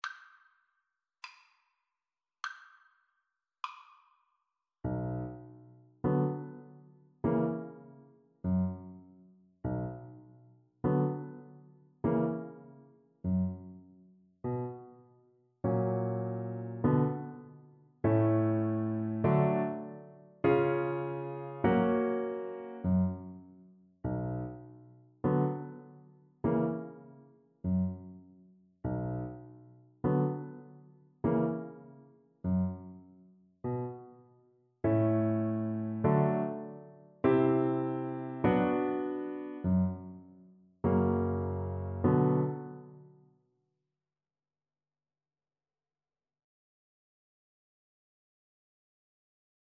Moderato
Classical (View more Classical Viola Music)